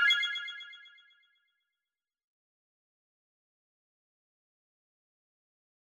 confirm_style_4_echo_005.wav